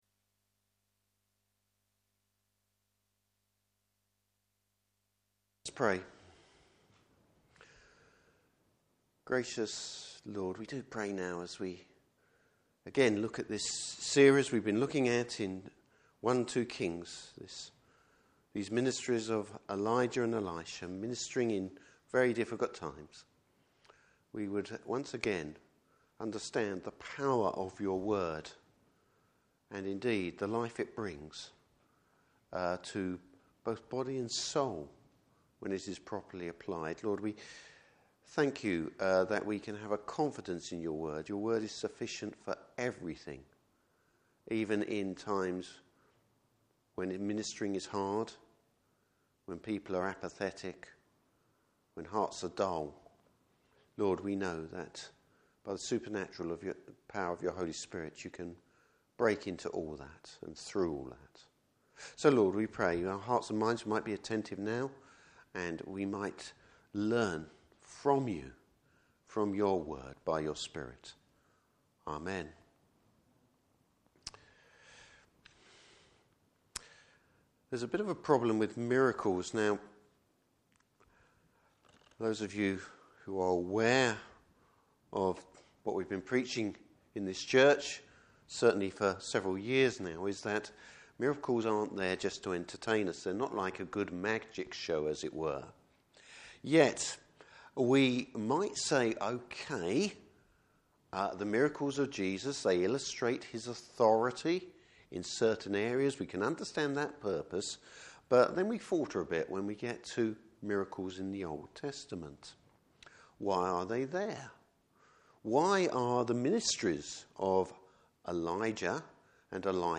Service Type: Evening Service Bible Text: 2 Kings 4:38-44.